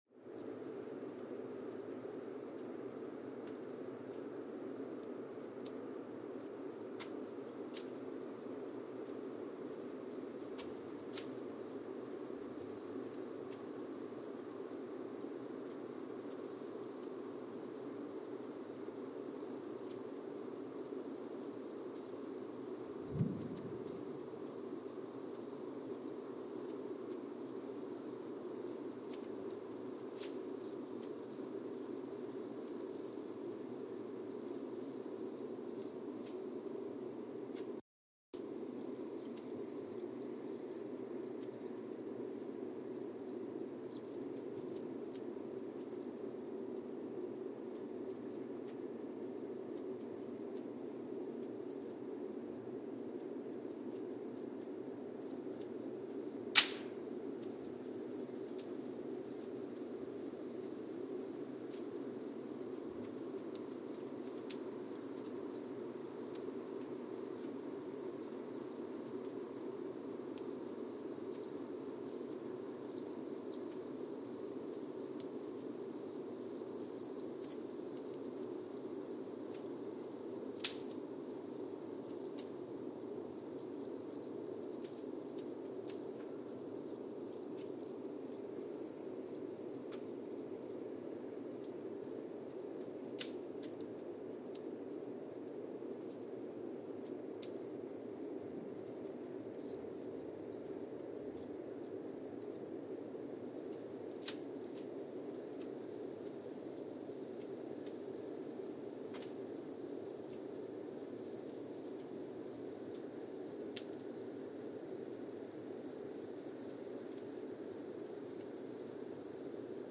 Mbarara, Uganda (seismic) archived on February 1, 2021
No events.
Sensor : Geotech KS54000 triaxial broadband borehole seismometer
Speedup : ×1,800 (transposed up about 11 octaves)
Loop duration (audio) : 05:36 (stereo)